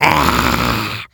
Зомби рычит. Пойдет для игр